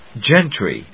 /dʒéntri(米国英語), ˈdʒentri:(英国英語)/